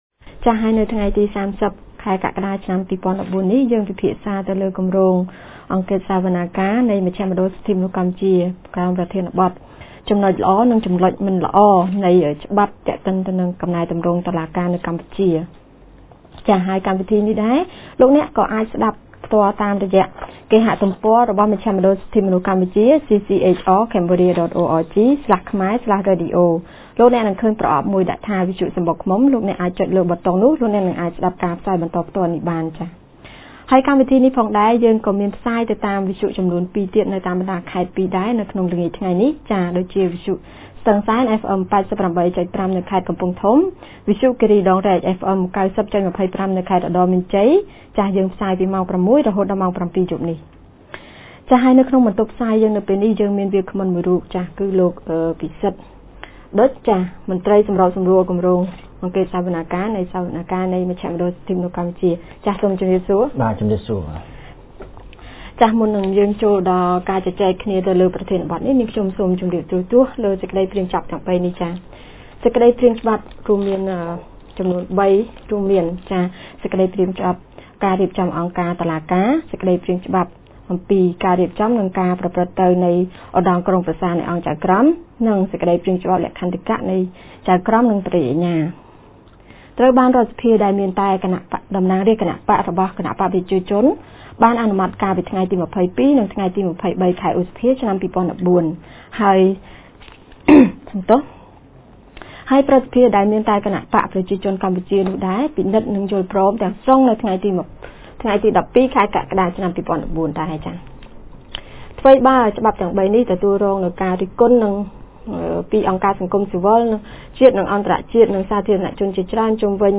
On 30 July 2014, CCHR TMP conducted a radio talk debating on the positive and negative provisions of the judicial laws, which have been promulgated by the King.